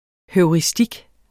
Udtale [ hœwʁiˈsdig ]